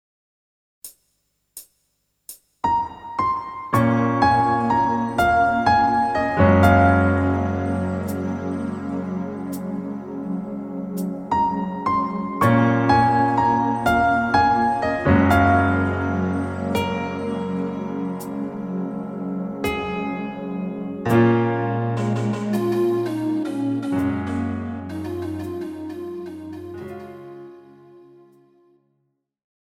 KARAOKE/FORMÁT:
Žánr: Pop
BPM: 126
Key: Bbm
MP3 ukázka